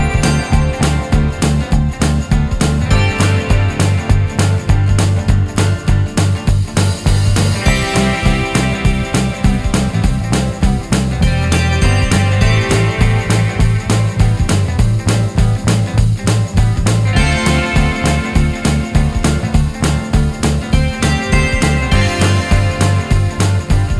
Minus Rhythm Guitar Pop (1980s) 3:50 Buy £1.50